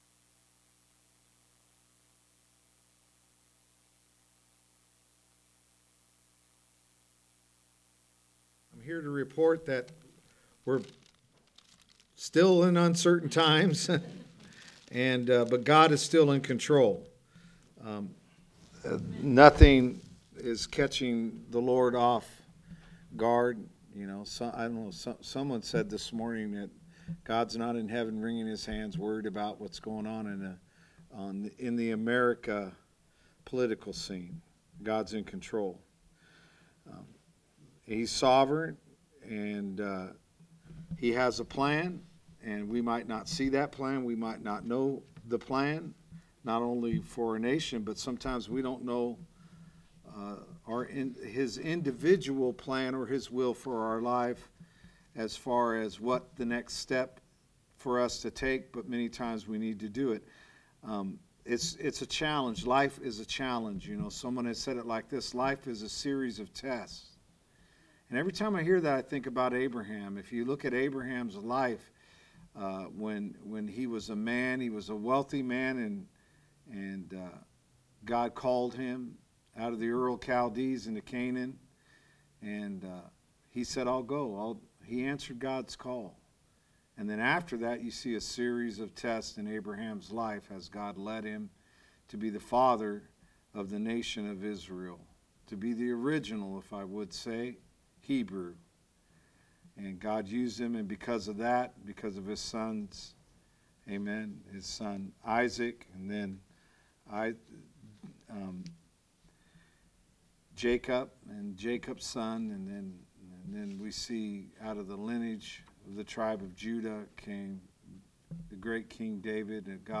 Message: “Sunday Morning”